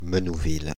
Menouville (French pronunciation: [mənuvil]
Fr-Paris--Menouville.ogg.mp3